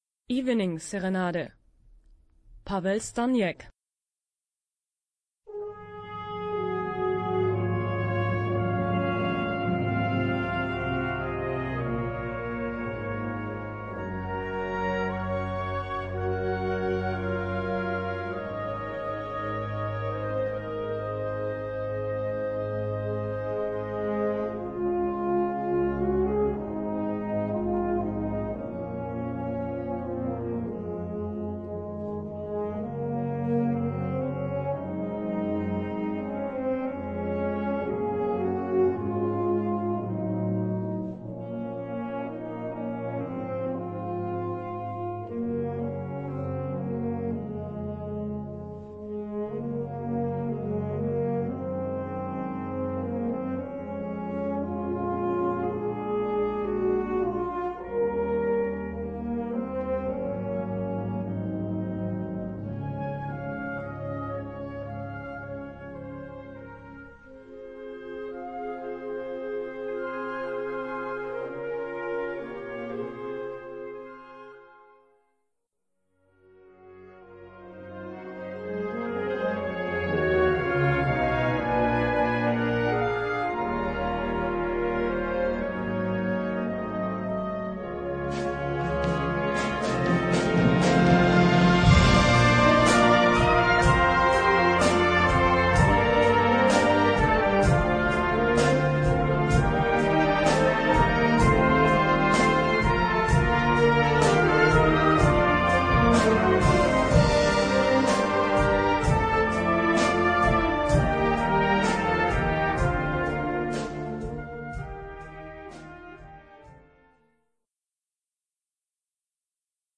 Gattung: Serenade
Besetzung: Blasorchester